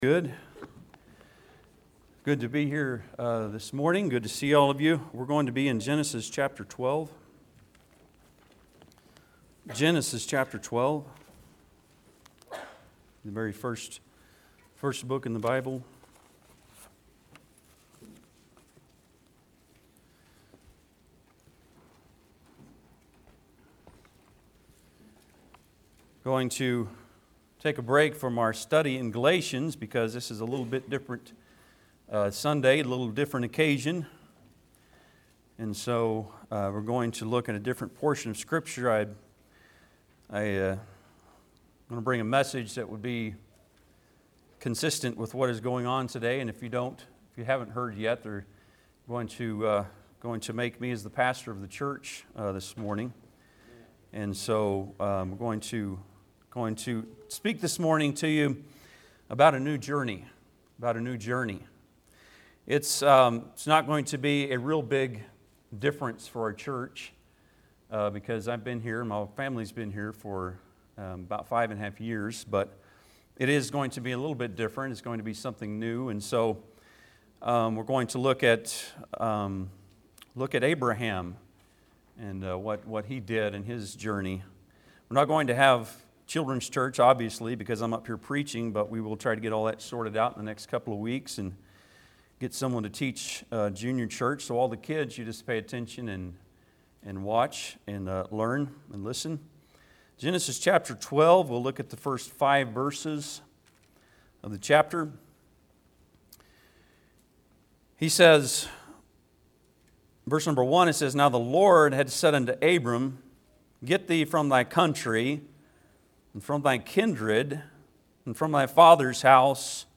Genesis 12:1-5 Service Type: Sunday am Bible Text